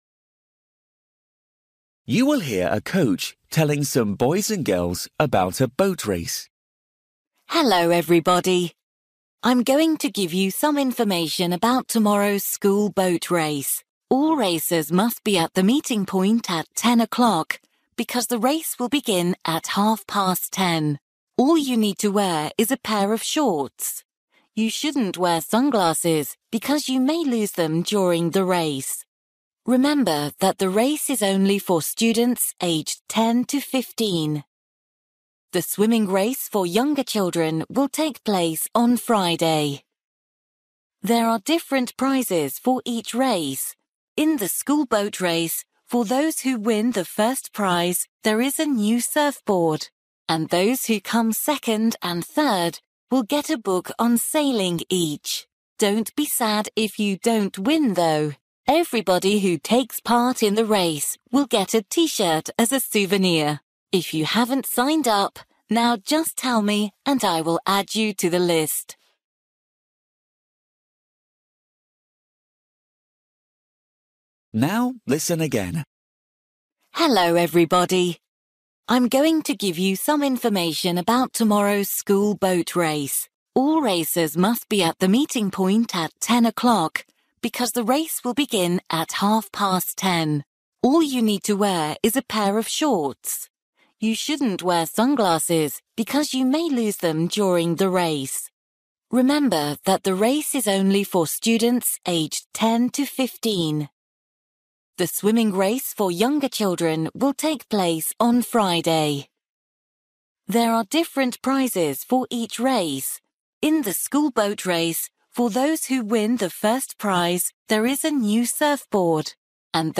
You will hear a coach telling some boys and girls about a boat race.